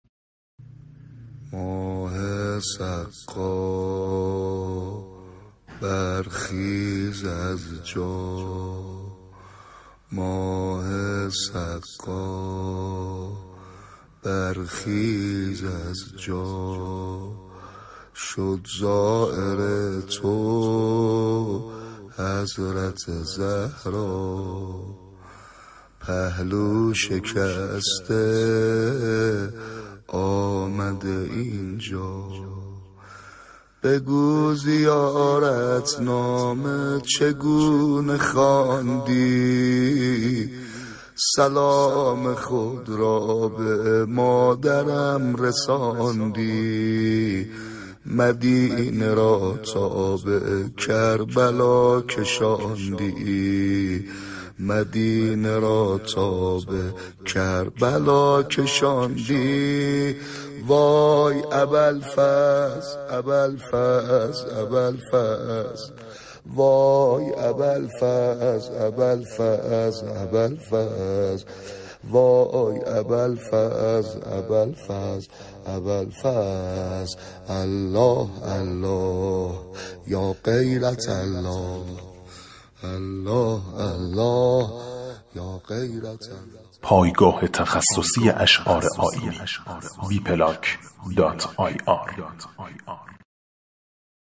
نوحه